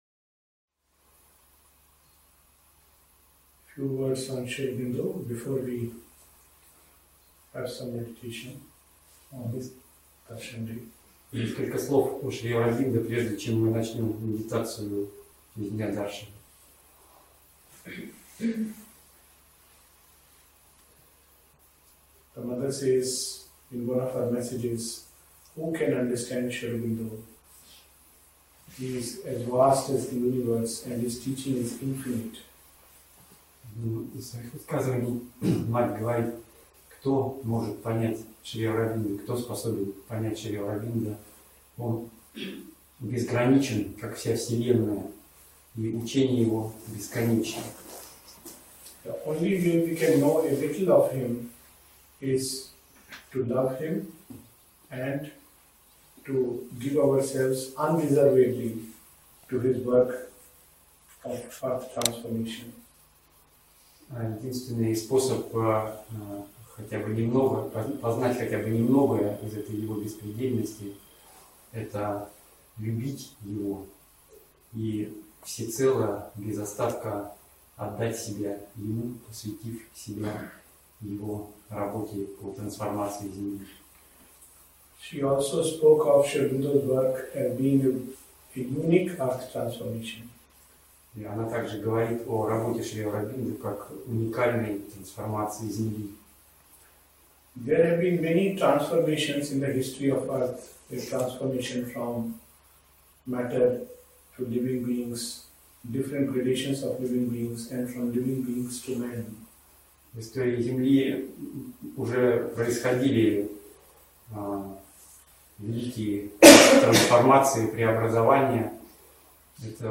Третья лекция проводилась в День Даршана 15 Августа.